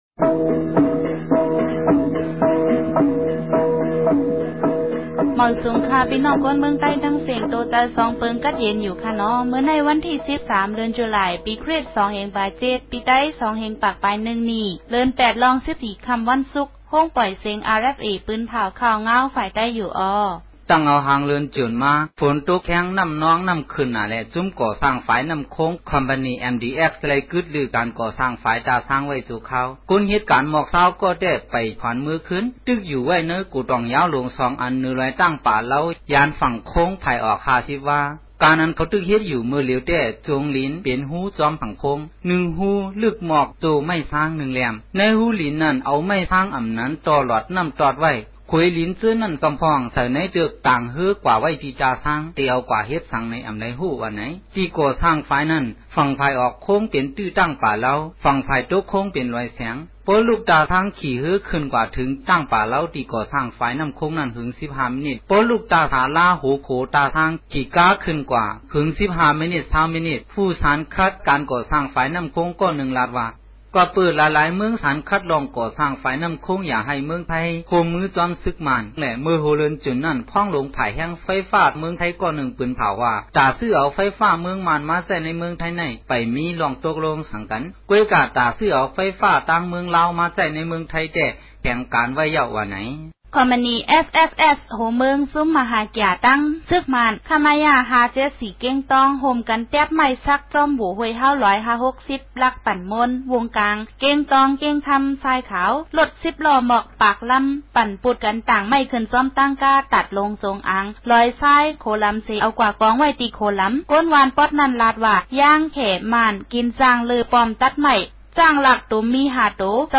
ရြမ်းဘာသာ အသံလြင့်အစီအစဉ်မဵား